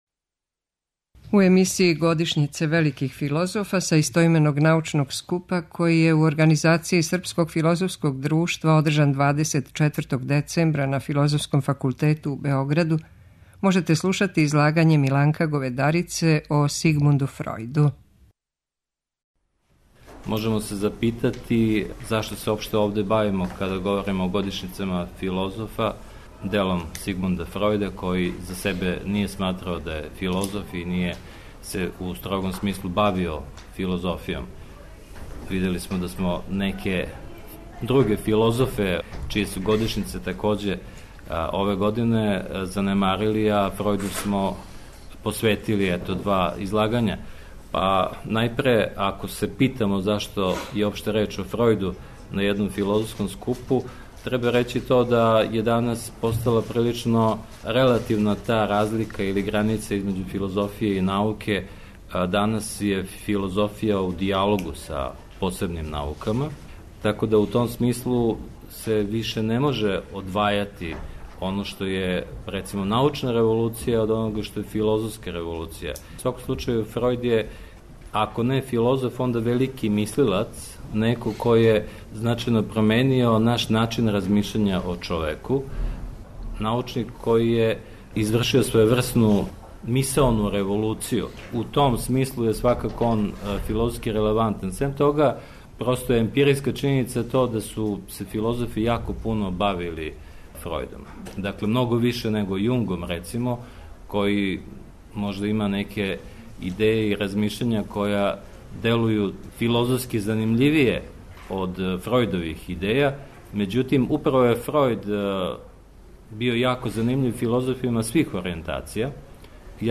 Научни скуп Годишњице великих филозофа Српско филозофско друштво из Београда организује осми пут.
преузми : 8.83 MB Трибине и Научни скупови Autor: Редакција Преносимо излагања са научних конференција и трибина.